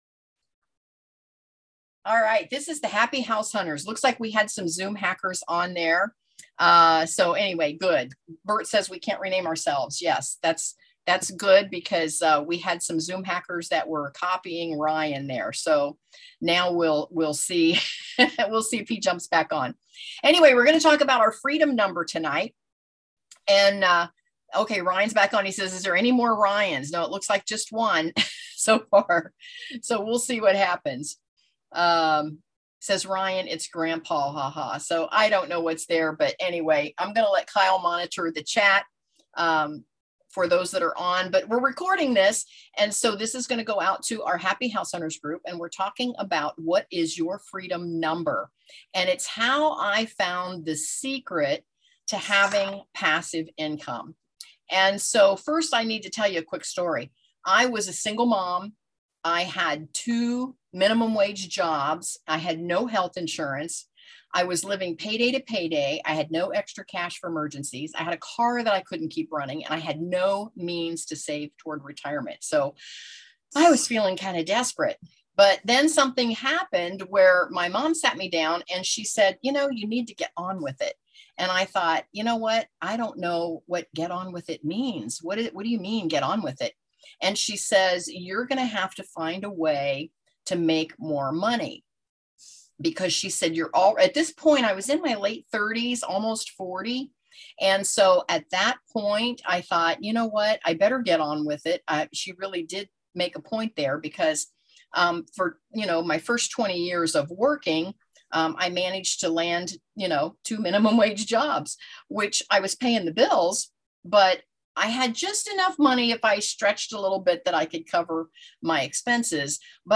Disclaimer: Excuse the zoom bombers trying to disrupt our webinar chat option -- we finally just ignored them.